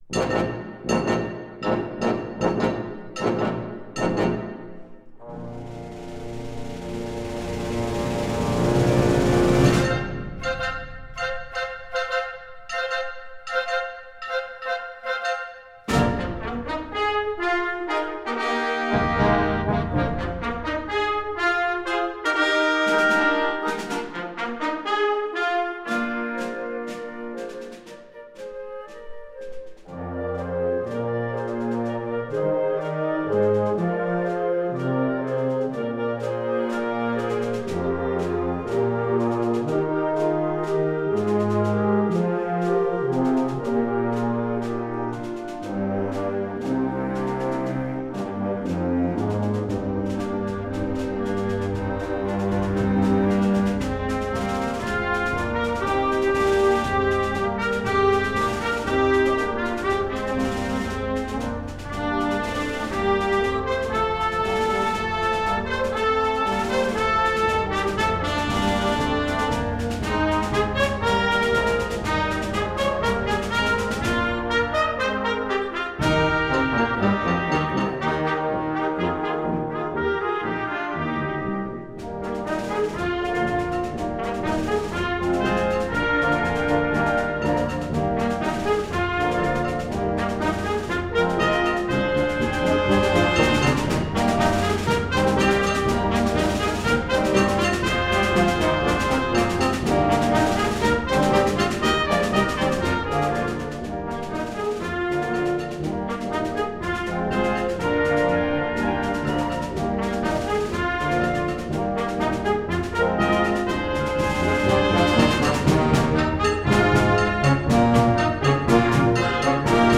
Gattung: Konzertwerk fürJugendblasorchester
Besetzung: Blasorchester